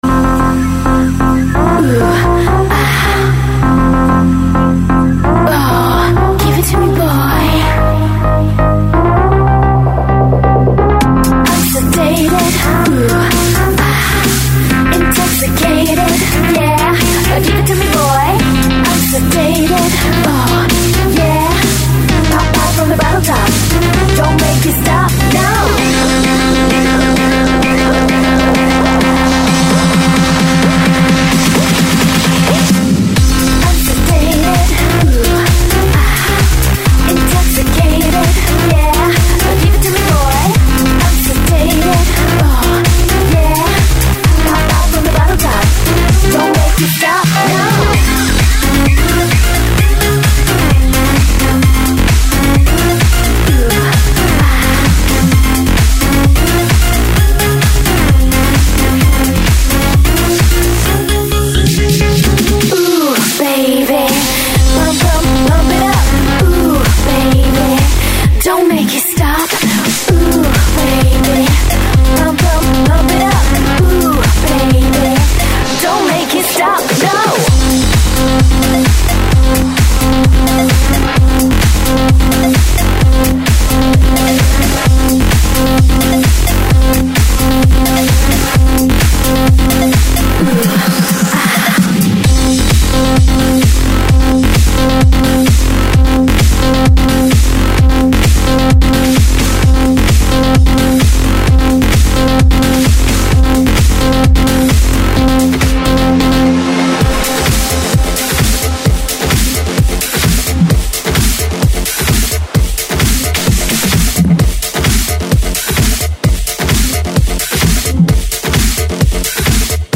клубный музон